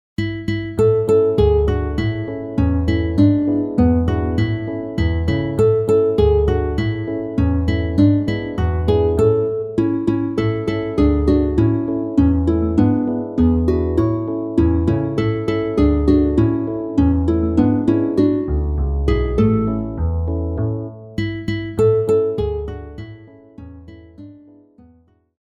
RÉPERTOIRE  ENFANTS